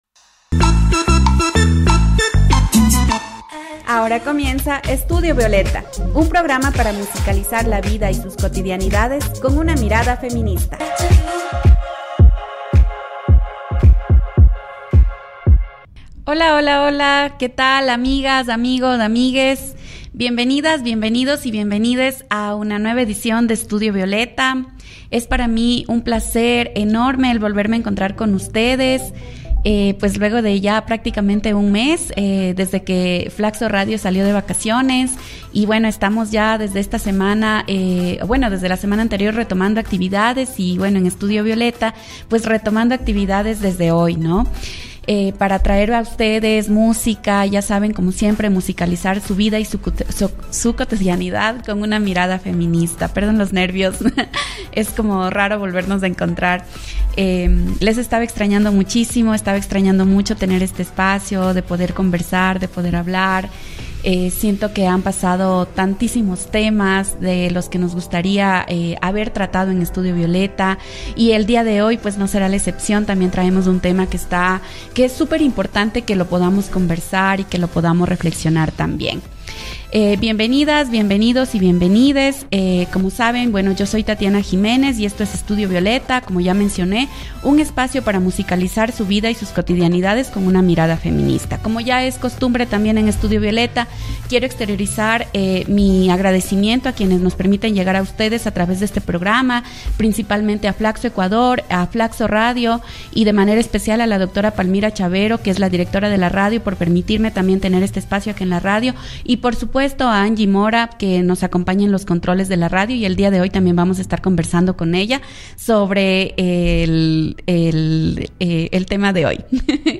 Llegamos a la sesión 12 de nuestro programa que musicaliza la vida y sus cotidianidades con una mirada feminista y queremos hacerlo hablando de fútbol femenino.